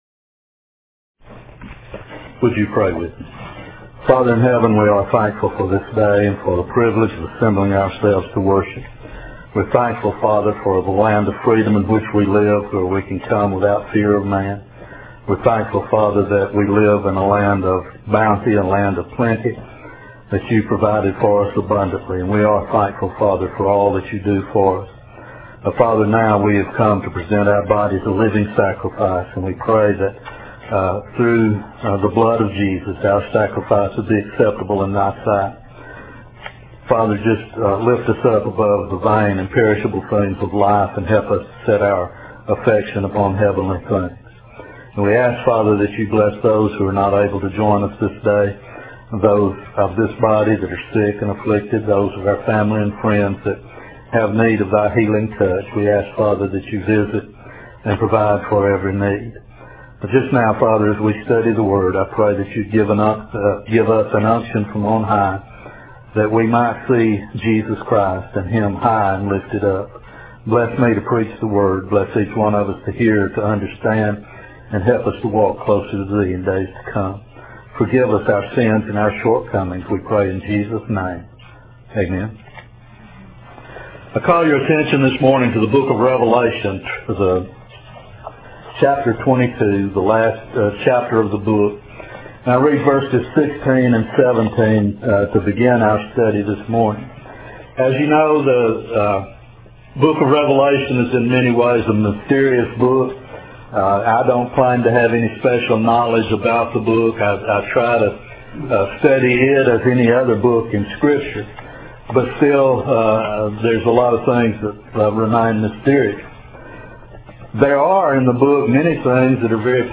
The Bright And Morning Star Aug 7 In: Sermon by Speaker